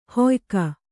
♪ hoyka